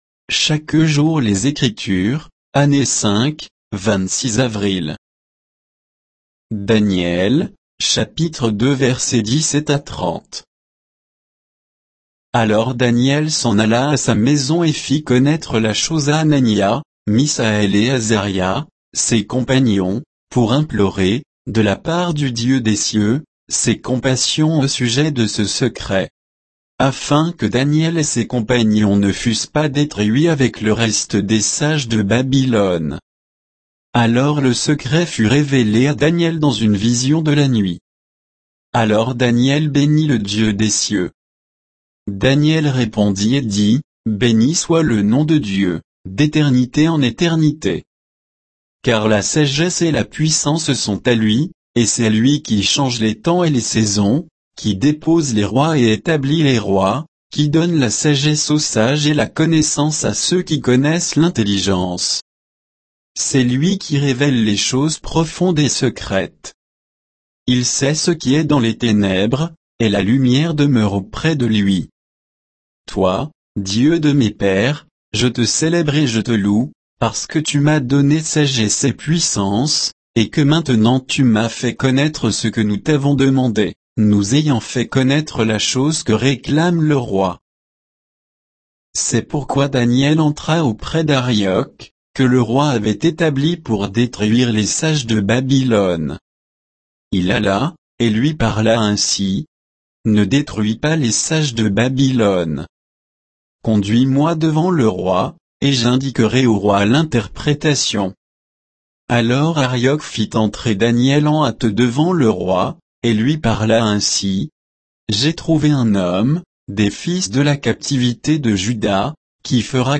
Méditation quoditienne de Chaque jour les Écritures sur Daniel 2, 17 à 30